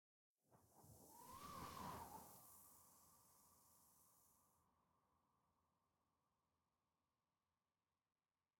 movingsand3.ogg